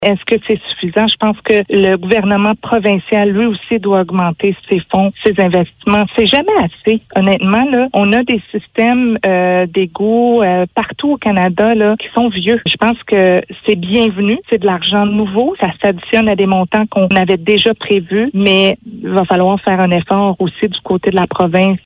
La députée de Pontiac, Sophie Chatel, affirme toutefois que l’action seule du fédéral ne sera pas suffisante pour résoudre cet enjeu :